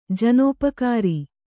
♪ janōpakāri